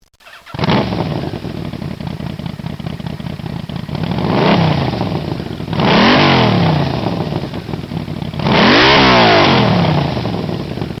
Frustar aggressivt och skrämmer slag på barn och gamla tanter.